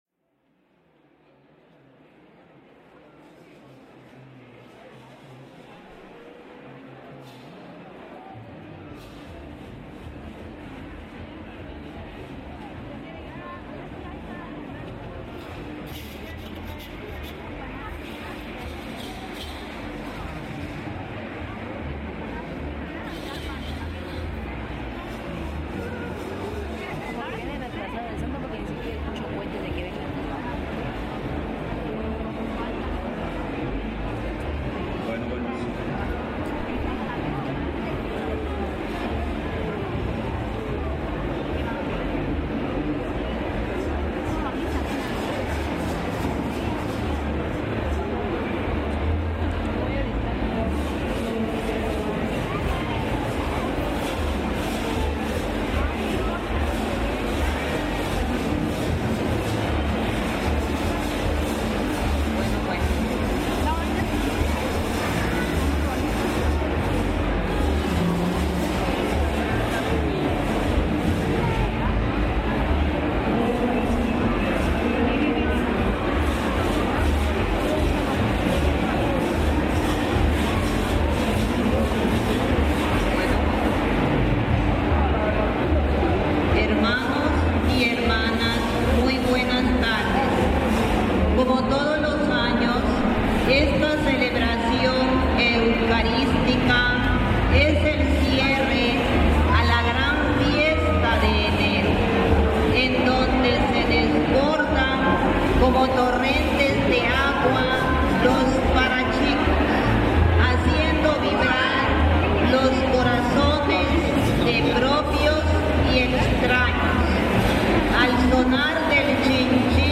Misa de Parachicos
La misa del 23 de enero, conocida como la Misa de Los Parachicos es una costumbre desde hace mucho tiempo, cuyo objetivo es el de hacer el cambio de prioste ante las autoridades religiosas, acto en el que participa gente del pueblo y por supuesto, los parachicos.
Una vez que termina la misa hay una pequeña ceremonia en la que los priostes que terminan su manda dicen unas palabras de agradecimiento; asimismo los que reciben tambien expresan la alegría que siente al recibir al Patrón de la Fiesta, al mismo tiempo que se comprometen en llevar a cabo todas las actividades tradicionales durante todo el año.
Algunos parachicos lloran al estar cantando ya que no saben si el próximo año volverán a bailar; mientras el patrón de los parachicos frente a San Sebastián, chicotea a los parachicos que hacen promesas de bailar por 3 o 4 años, según sea lo que le pindan al santo.
Equipo: Grabadora Sony ICD-UX80 Stereo, Micrófono de construcción casera ( más info ) Fecha: 2012-08-04 16:45:00 Regresar al índice principal | Acerca de Archivosonoro